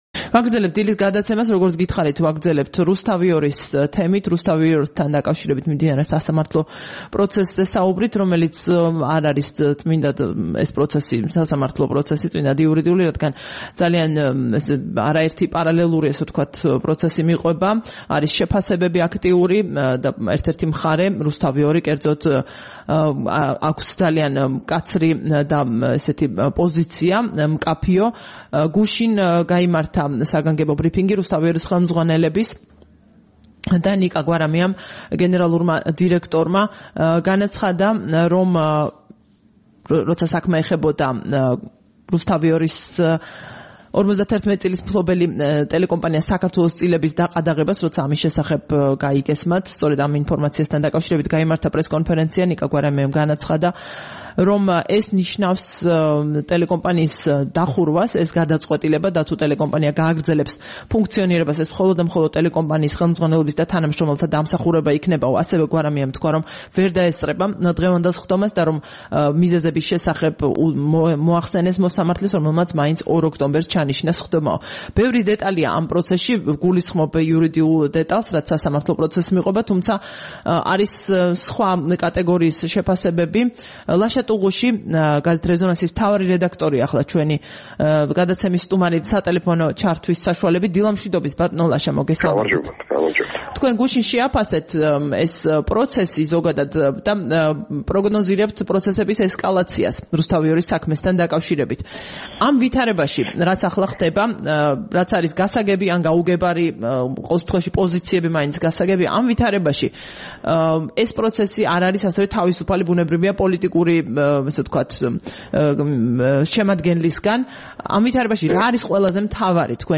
2 ოქტომბერს რადიო თავისუფლების დილის გადაცემაში
საუბარი